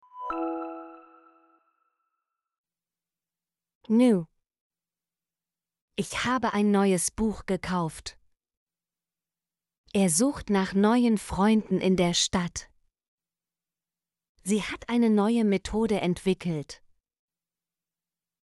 new - Example Sentences & Pronunciation, German Frequency List